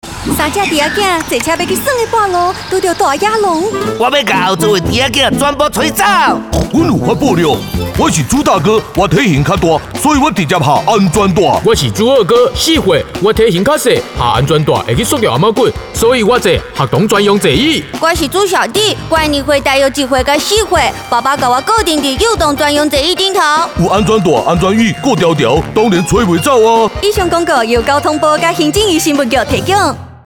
台語配音 國語配音 女性配音員